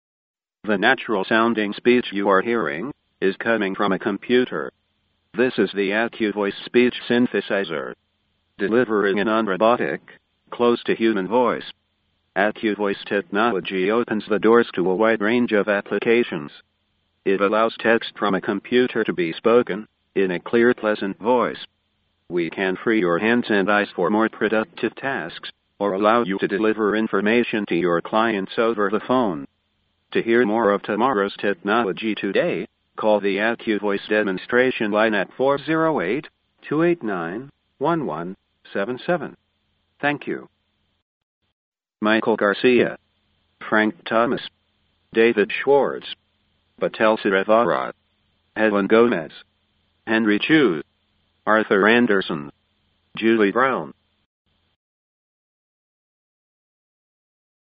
75. English male voice and names. 0.55